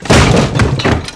crateBreak1.ogg